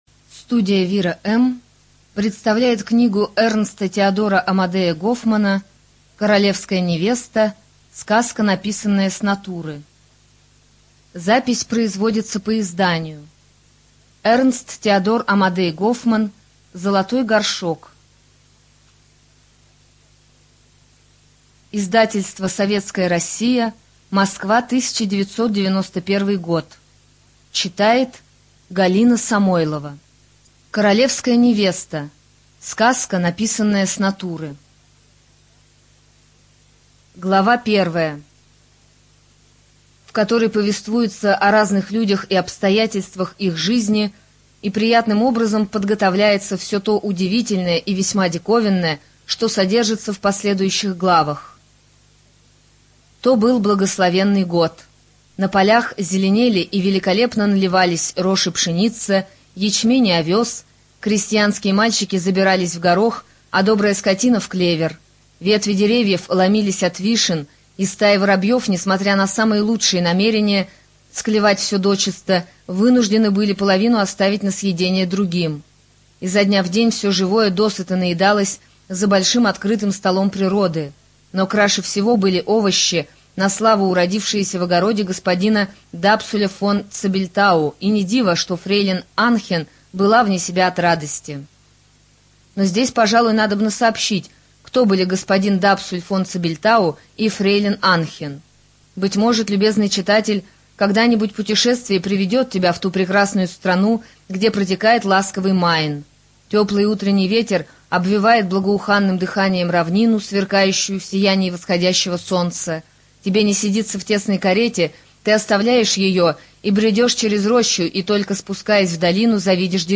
Студия звукозаписиВира-М
Детская литература